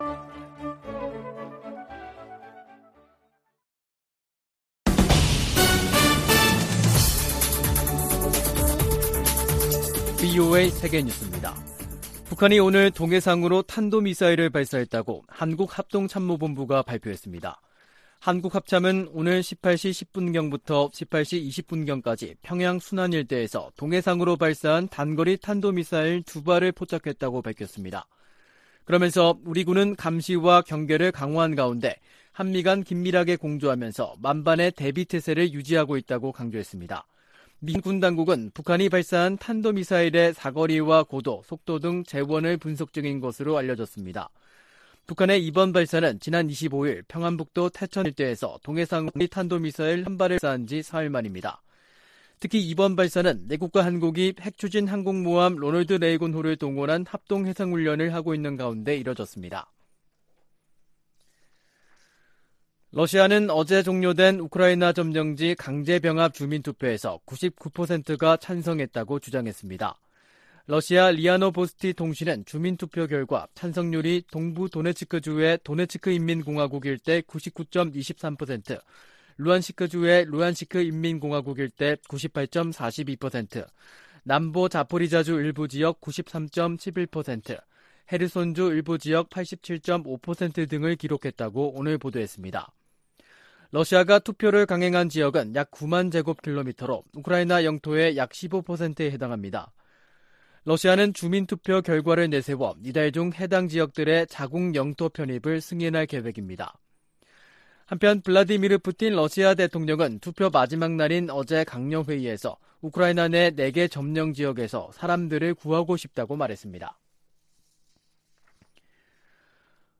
VOA 한국어 간판 뉴스 프로그램 '뉴스 투데이', 2022년 9월 28일 3부 방송입니다. 북한이 동해상으로 미상의 탄도미사일을 발사했다고 한국 합동참모본부가 밝혔습니다. 카멀라 해리스 부통령이 도쿄에서 한국 국무총리와 만나 북핵위협 해결을 위한 협력을 약속했습니다.